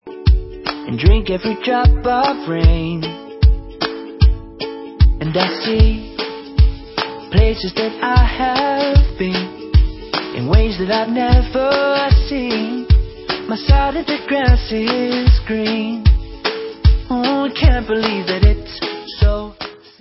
britští poprockeři